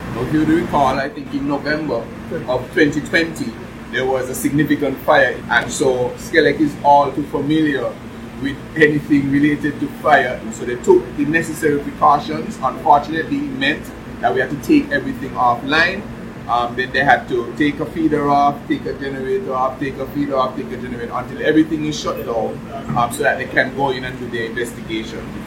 During that time, the minister was recorded live via Facebook at the power plant throughout the investigation, where he explained the precautionary steps SKELEC took to ensure the safety of the staff along with the facility: